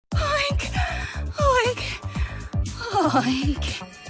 Play, download and share Oink 2 original sound button!!!!